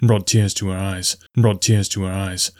Clicking Sound - Help!
I added a clip that demonstrates the noise (first section is unedited, second one I ran the “De-Clicker” effect multiple times).
I make sure that my computer has no other programs running, turn the Wi-fi & bluetooth off and record in a very quiet closet with my mic in a insulated spot.
DeClicker won’t get rid of the (digital ?) ringing noise: need Audacity’s spectral edit tools for that …